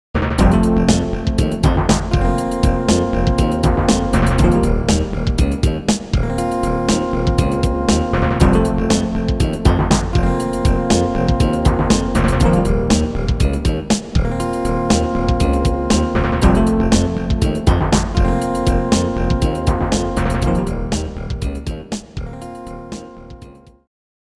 as recorded directly from the Roland MT-32 Sound Module